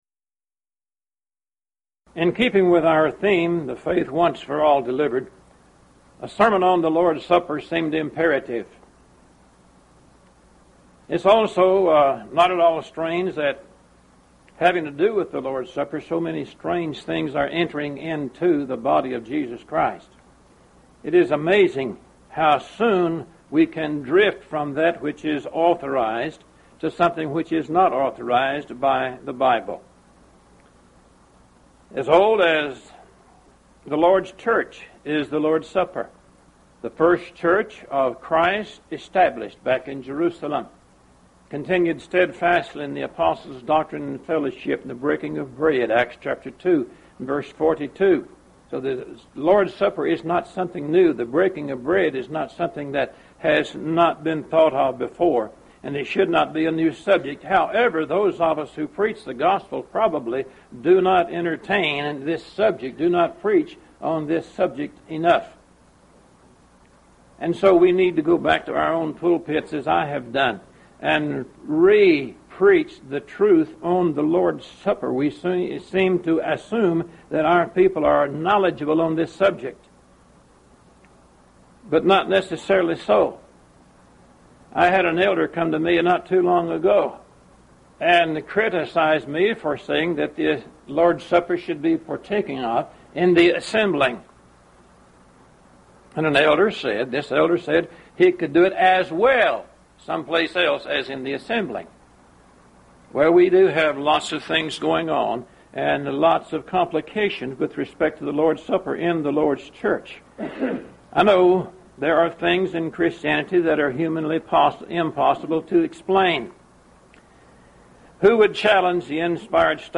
Event: 1st Annual Lubbock Lectures
lecture